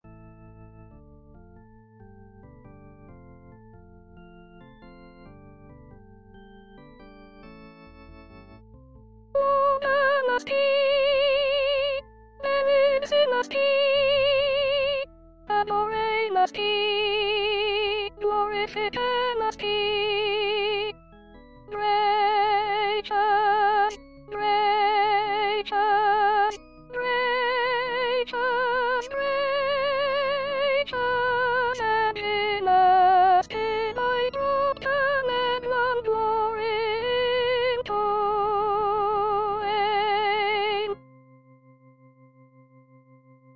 Chanté:     S1